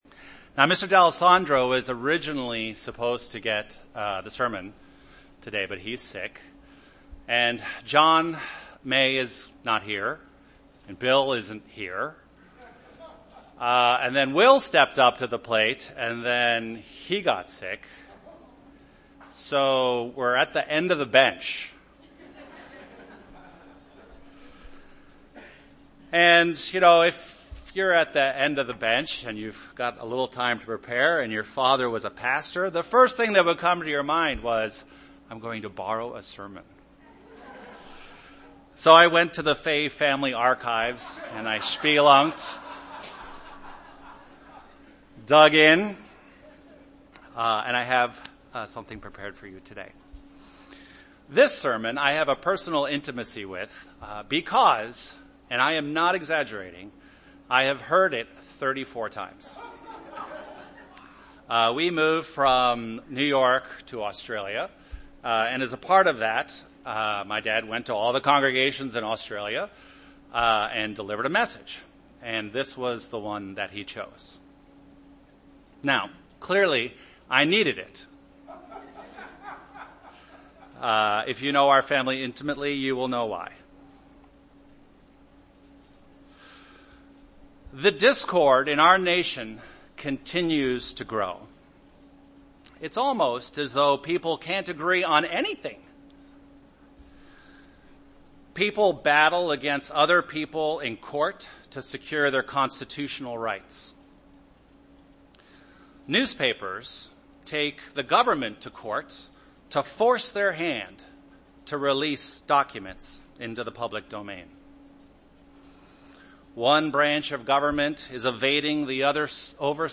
Sermons
Given in Chicago, IL